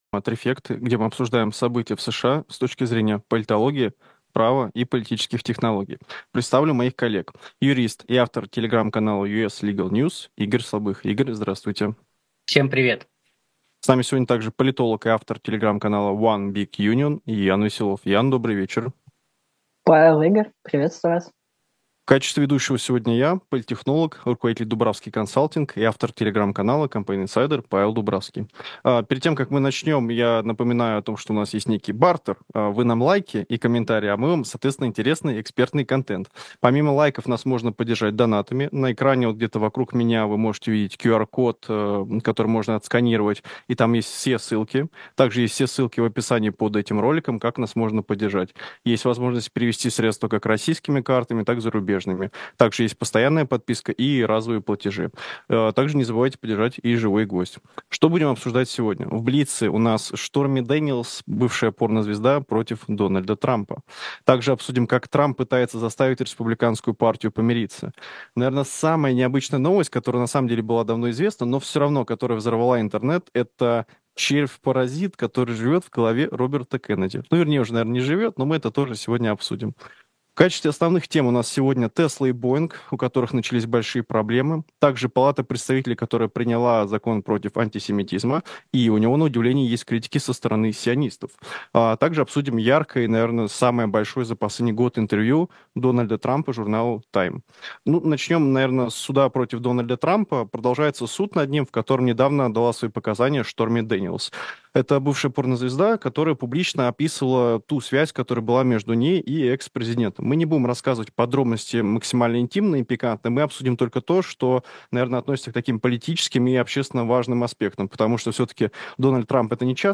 Закон против антисемитизма / 13.05.24 Скачать Подписаться на « Живой гвоздь » Поддержать канал «Живой гвоздь» В эфире еженедельная программа «Трифекты».